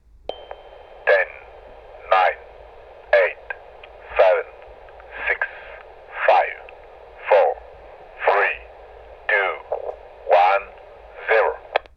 VOXMale-Compte-a-rebours-talkie-en-anglais-ID-1144-LS.wav